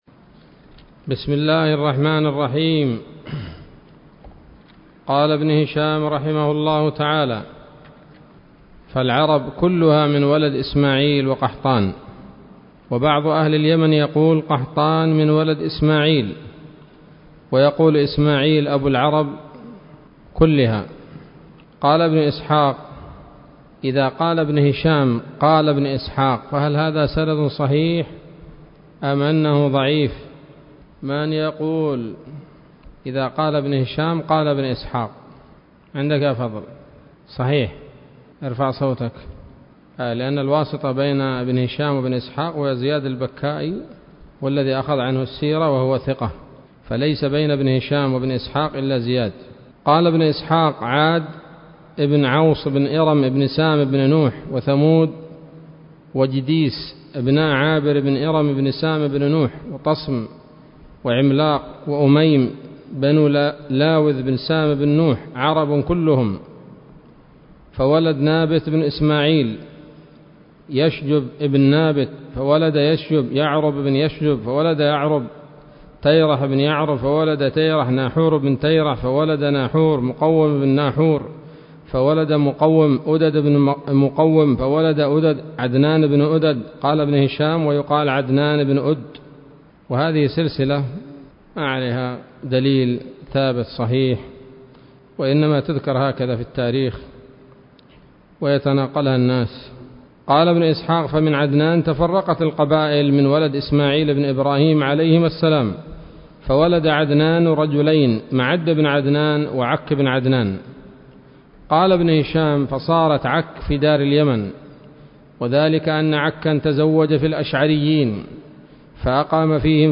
الدرس الثالث من التعليق على كتاب السيرة النبوية لابن هشام